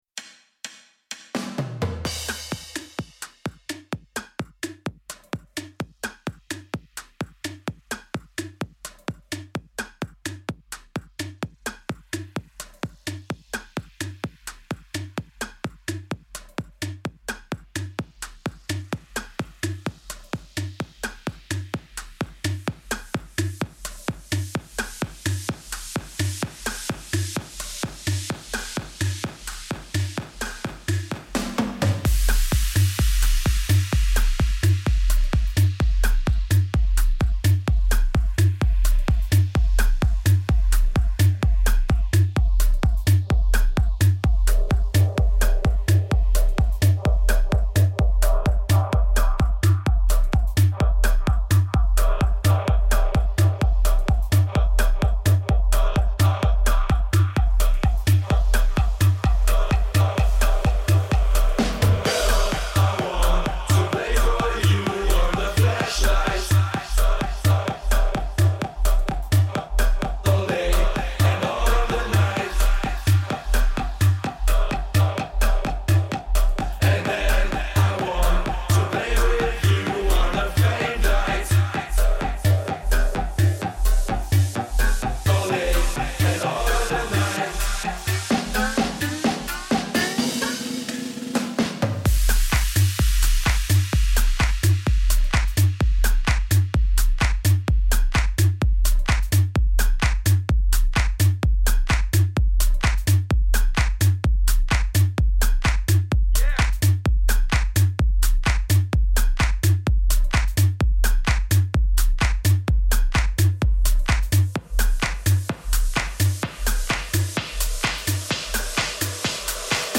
Its clubby house and a lot of fun.